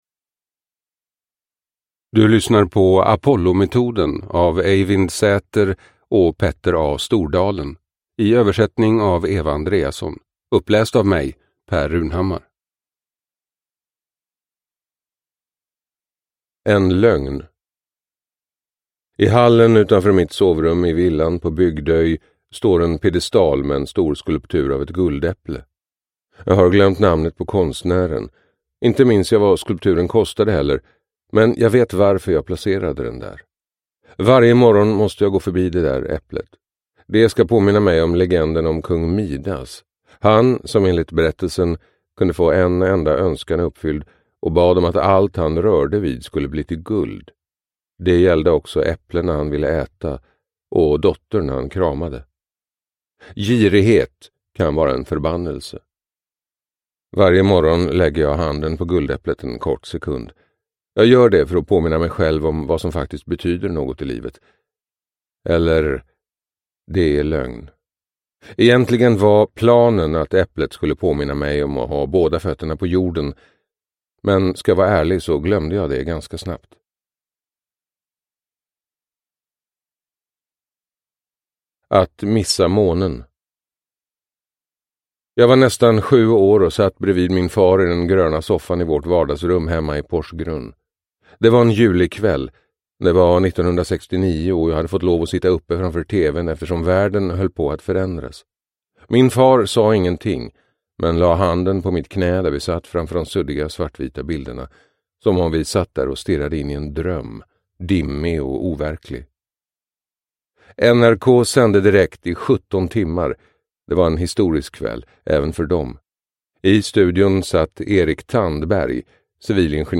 Apollometoden : sju strategier som fungerar i kris – Ljudbok – Laddas ner